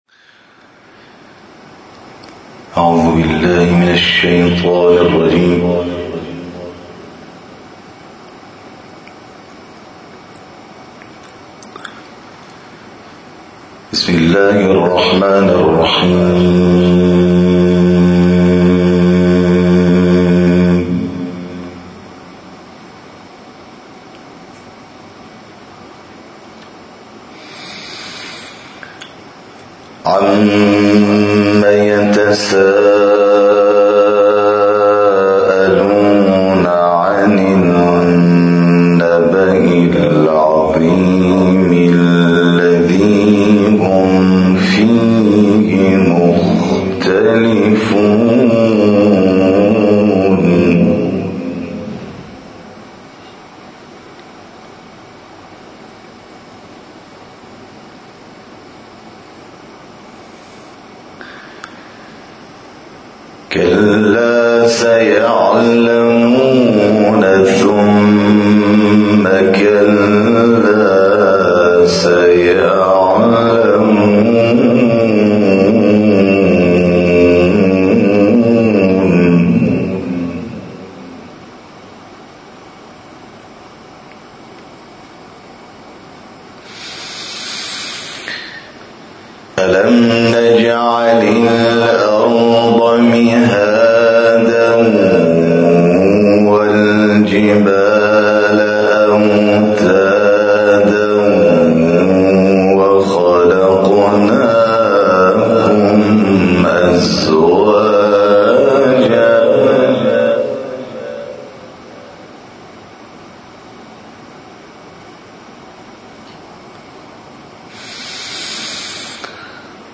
تلاوت
در هیئت زنجیرزنان تهران بیش از یک ساعت به تلاوت قرآن پرداخت.